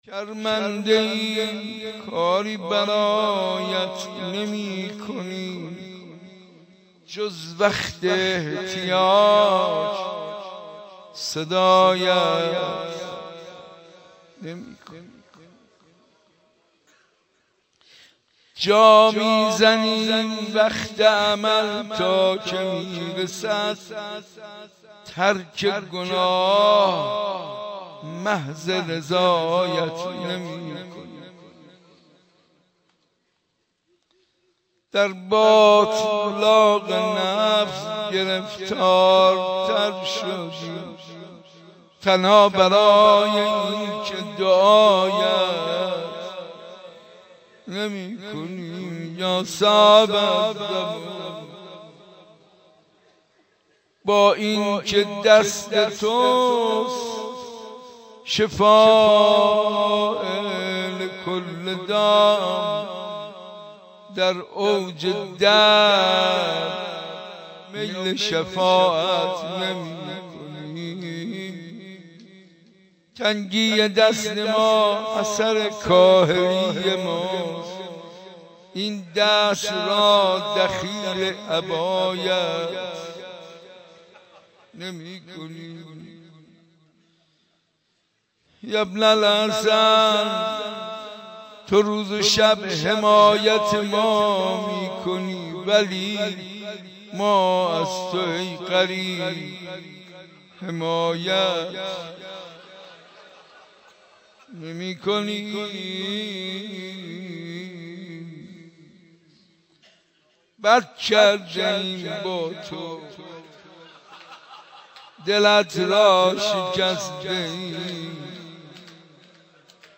مناجات با امام زمان عجل الله تعالی فرجه الشریف